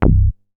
MoogDumb 007.WAV